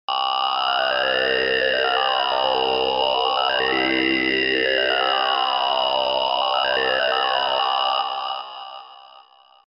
表情を付けた歌声（MP3）